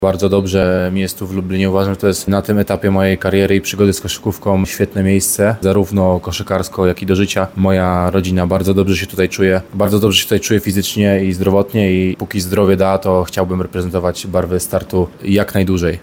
Komentował sam zawodnik